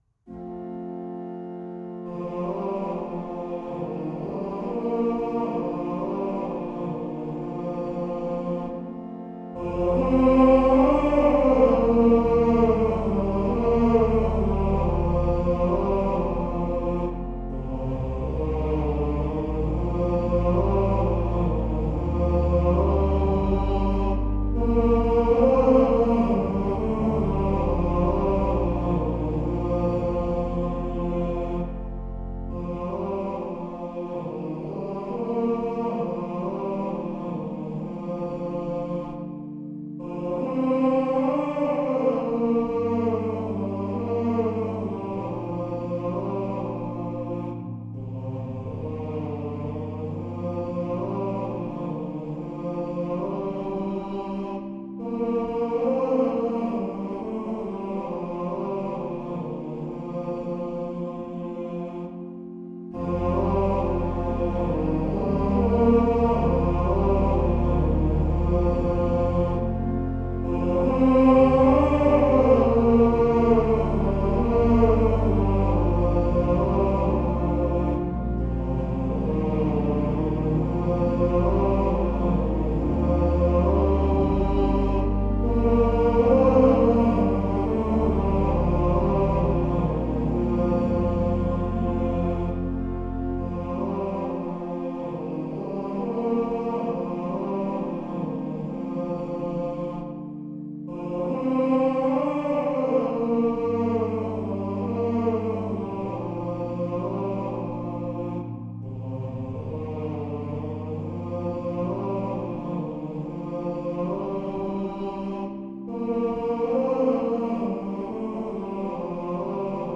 Since it has been the custom to sing Gregorian office hymns in the Choral Evensongs at the Dominicanenklooster (Dominican Convent) in Zwolle, I have composed an organ accompaniment each time.
o-blest-creator-zang-en-orgel.mp3